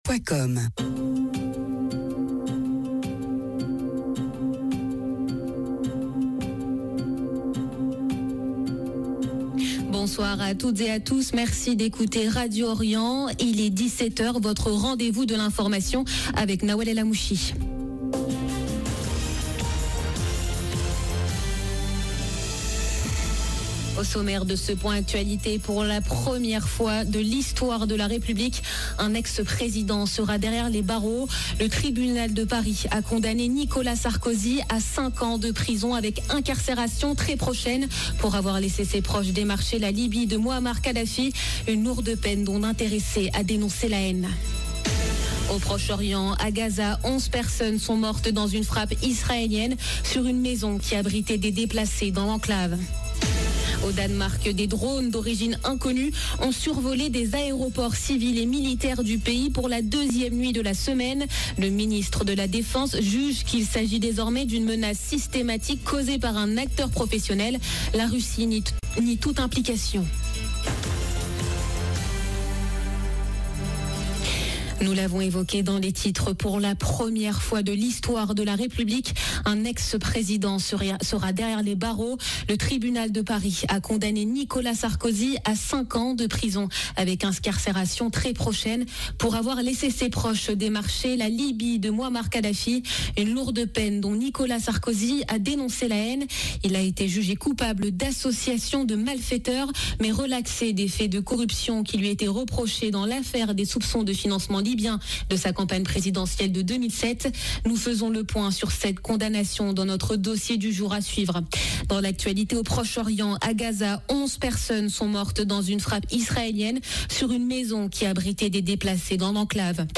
Journal de 17H du 22 septembre 2025